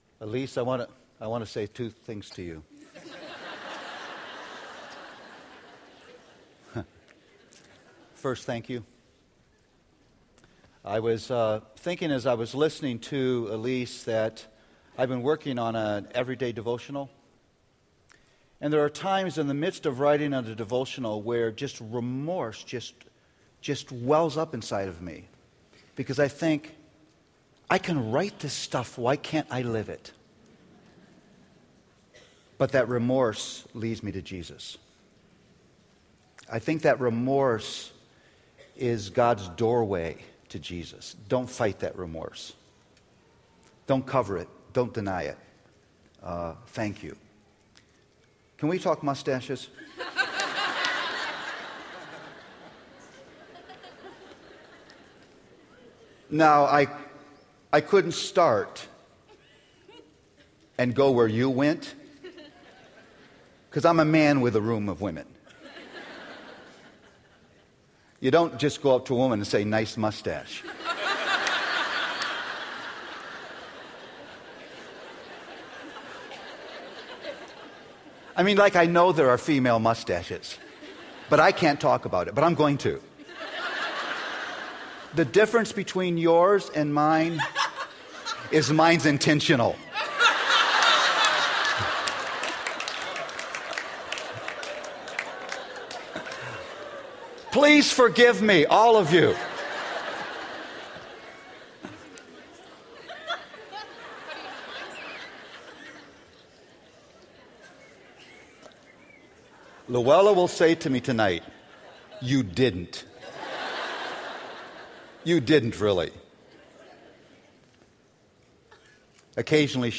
R13-Plenary-Session4-PDT.mp3